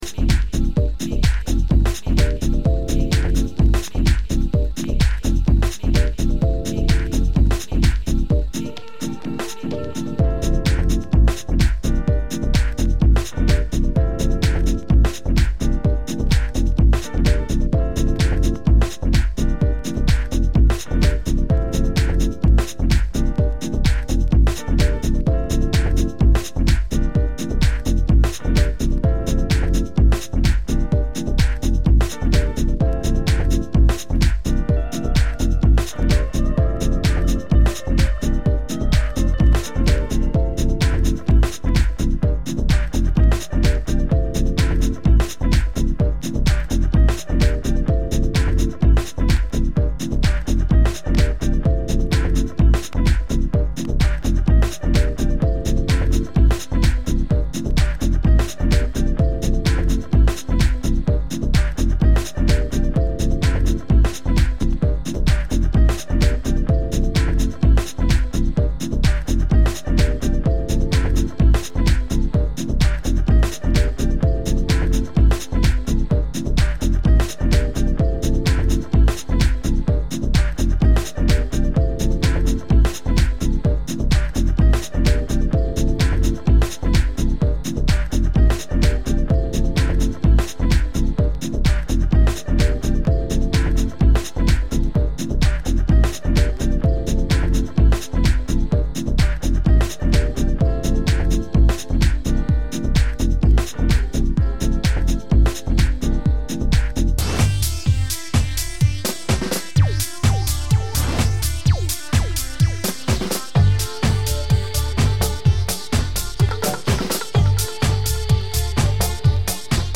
Genre: Deep House